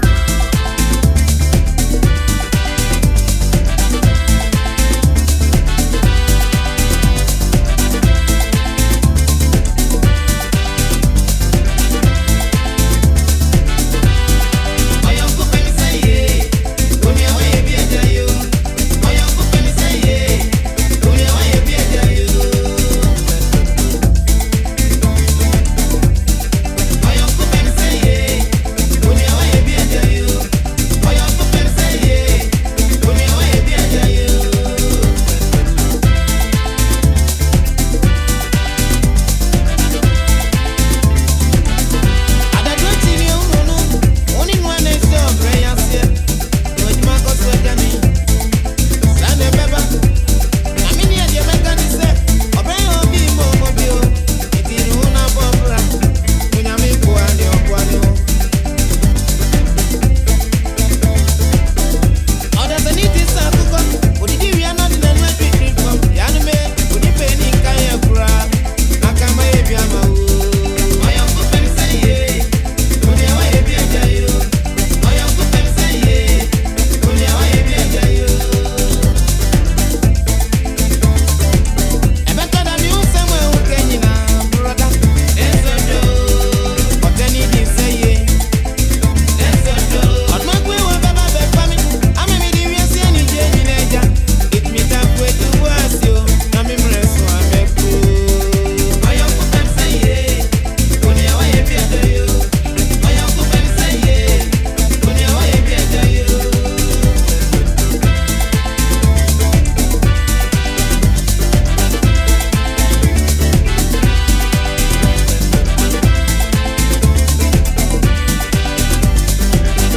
soothing melodies, and timeless rhythms.
a powerful highlife song that speaks about jealousy